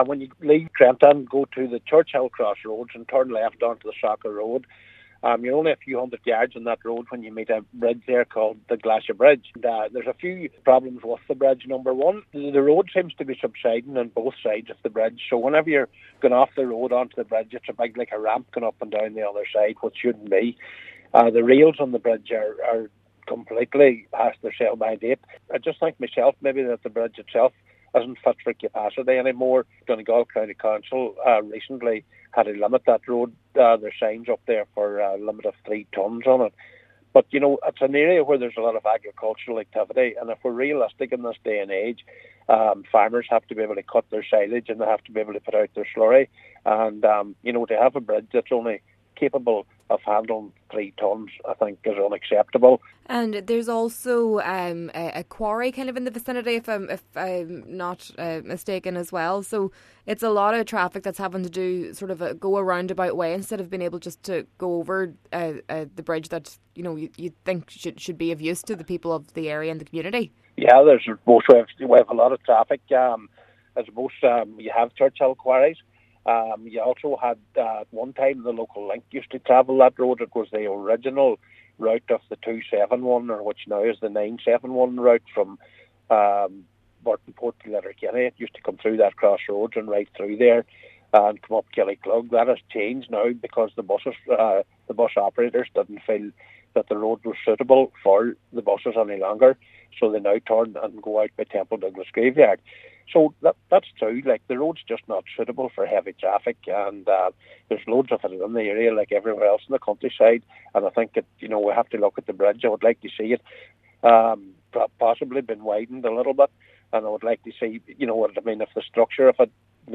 Cllr McBride says this is unacceptable, and work is needed at the bridge: